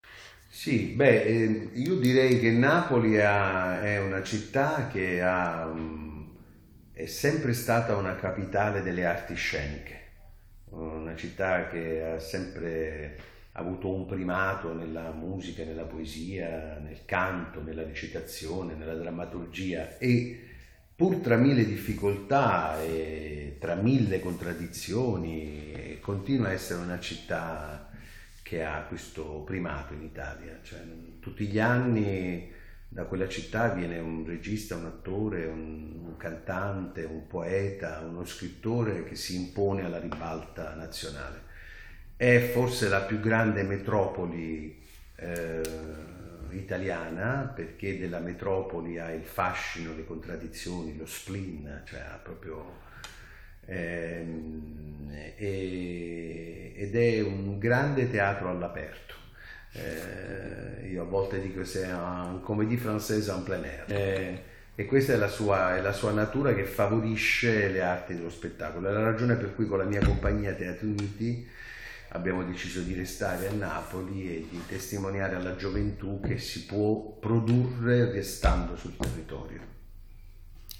Intervista.